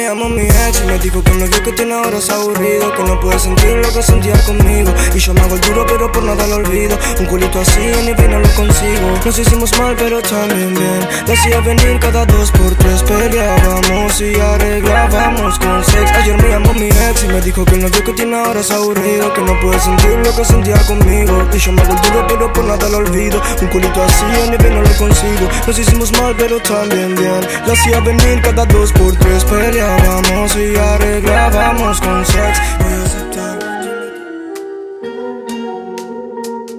Categoría Latin